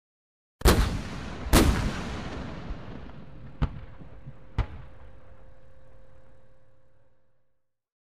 Звуки танка
Выстрел двух танков и точное попадание